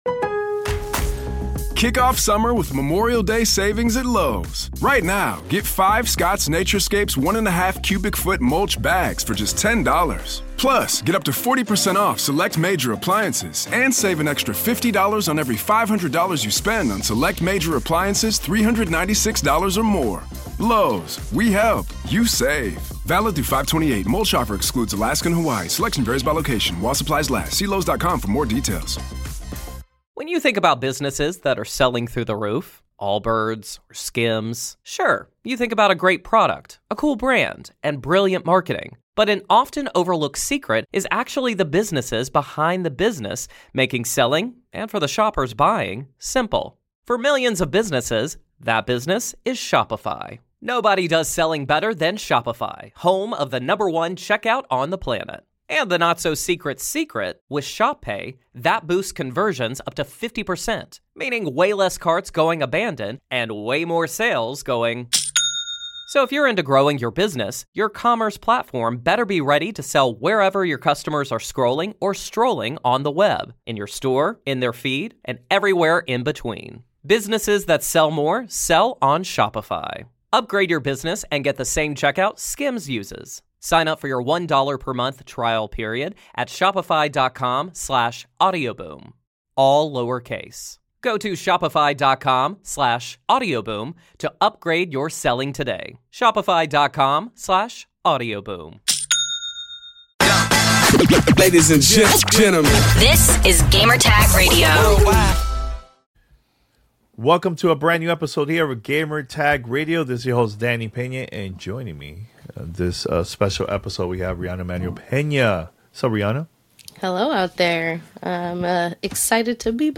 FBC: Firebreak Interview and Hands-On Impressions